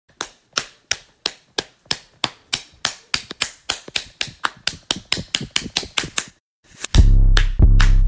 Clapping Cheeks Sound Effect Free Download
Clapping Cheeks